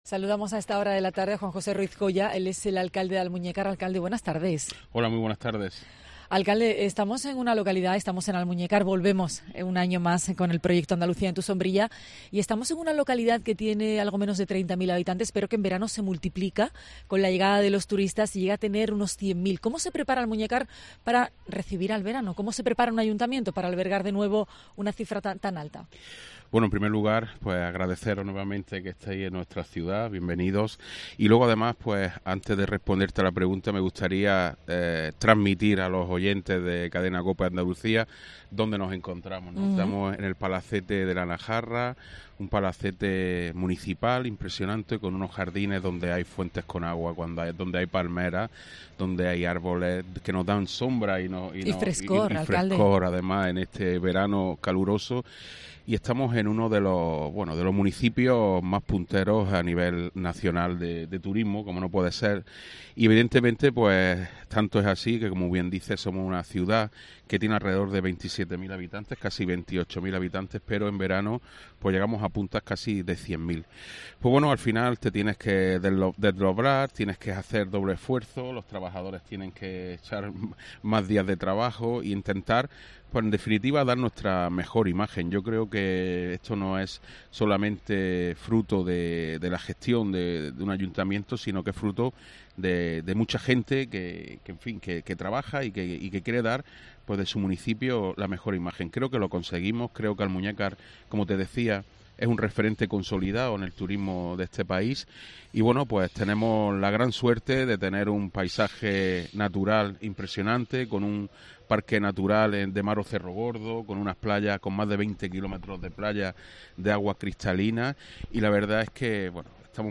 En COPE Andalucía hablamos con el alcalde del municipio, Juan José Ruiz Joya, sobre los retos que tiene en su gobierno para los agricutlores, el más importante: conseguir las canalizaciones de la presa de Rules, que lleva terminada más de quince años.